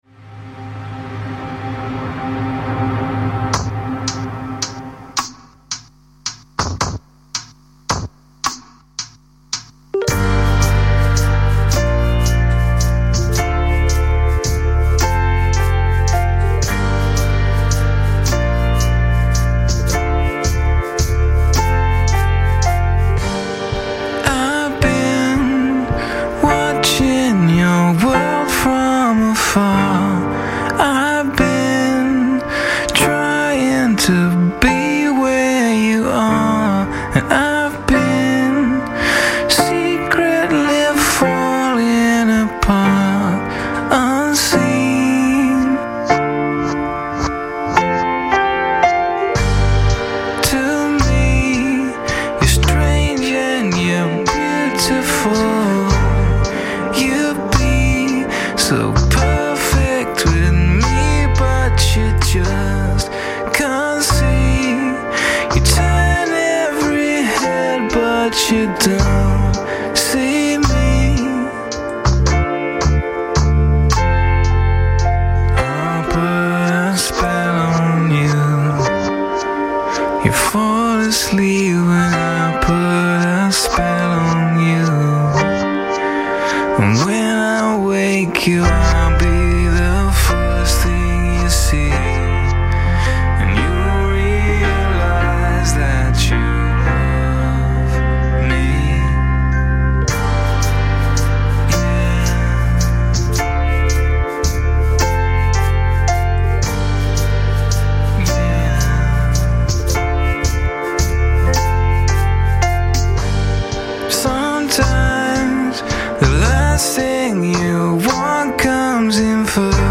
Жанр: Electronica-Chillout